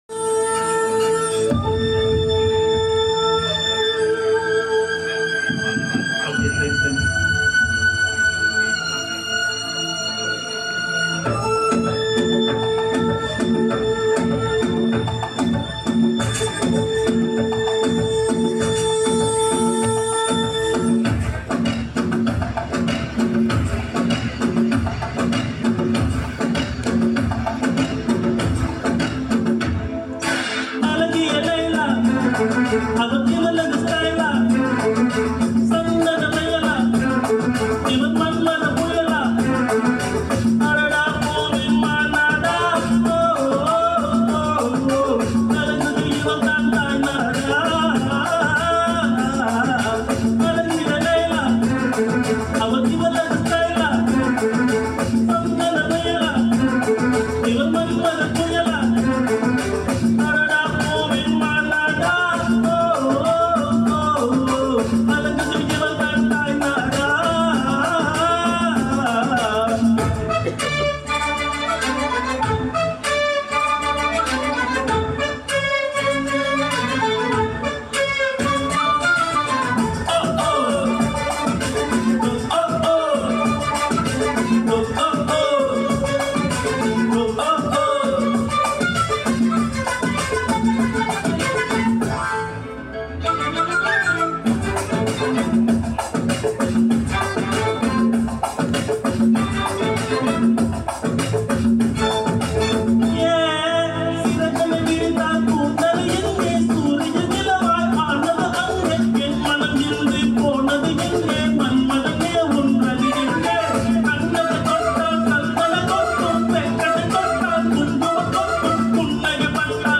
auditorium where I was the Organiser on Sunday 24th Nov 2024